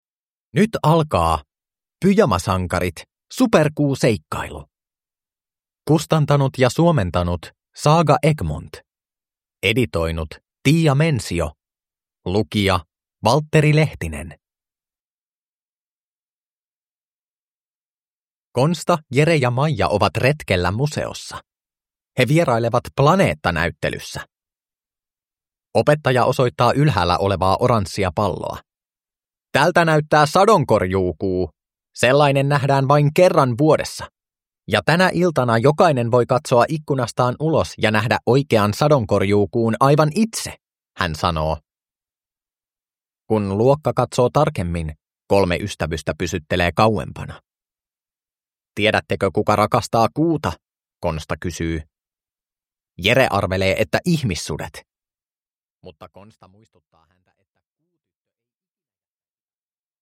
Pyjamasankarit – Superkuuseikkailu – Ljudbok